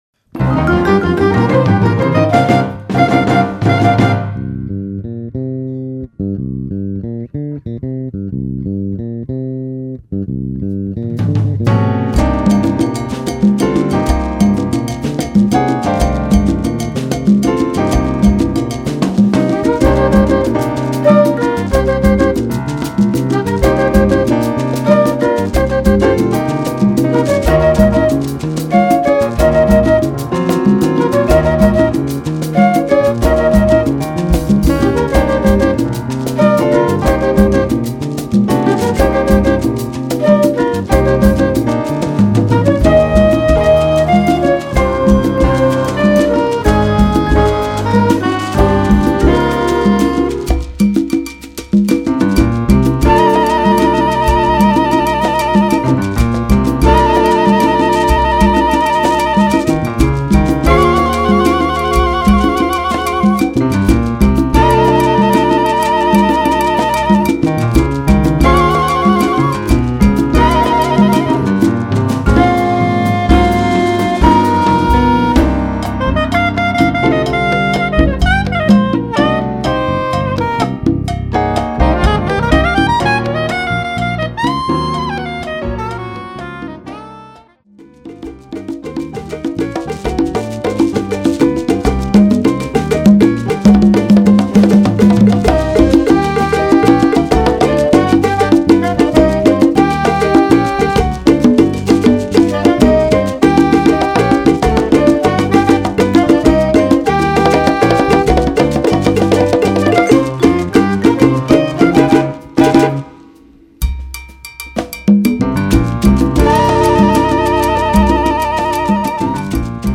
Category: combo (septet)
Style: bembe (6/8)
Solos: open